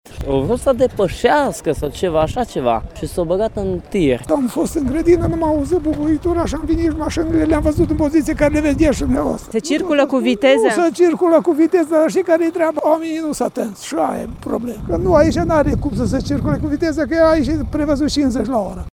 Martorii spun că au auzit un zgomot puternic, însă precizează că de obicei nu se circulă cu viteză în acea zonă: